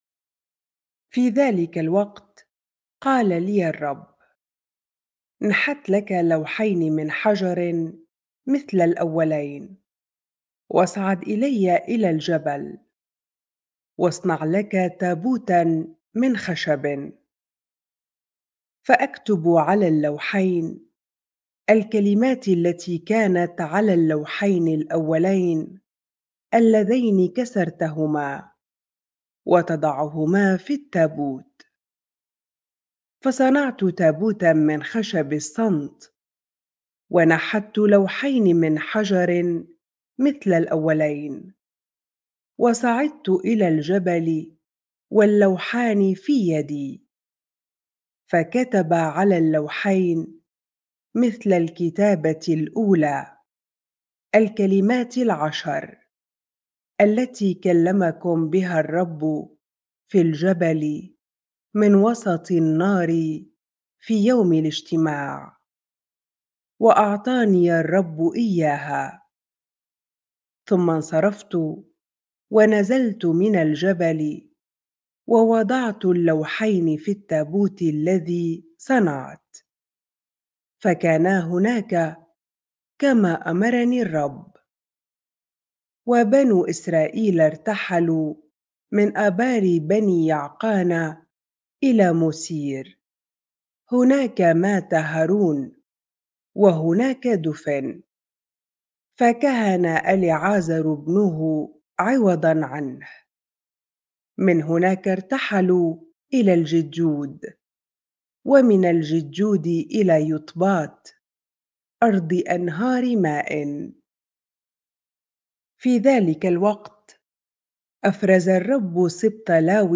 bible-reading-deuteronomy 10 ar